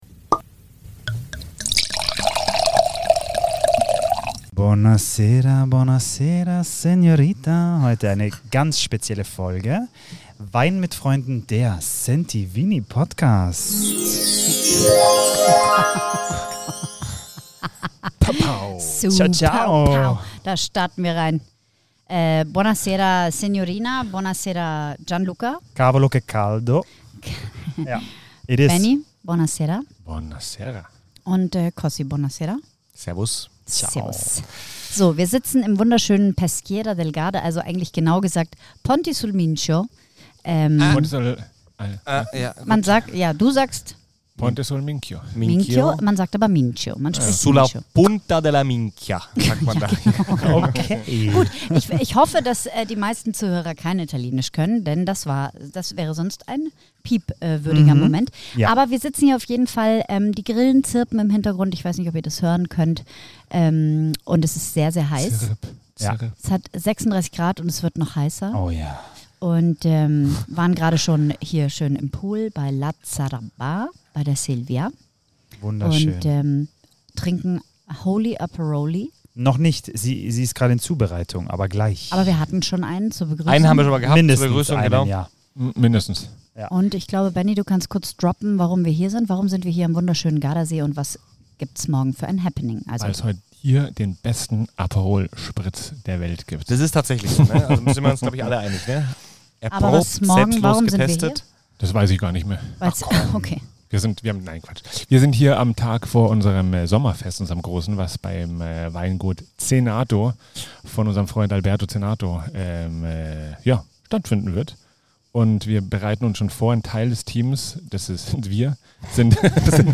Folge #80 senden wir euch live vom Gardasee.